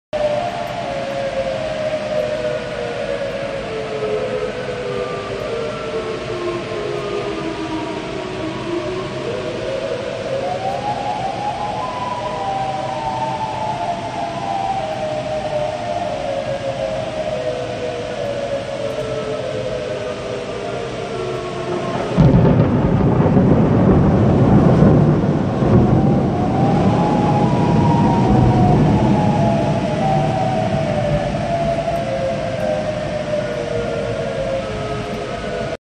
Chicago tornado sirens and a big boom.wav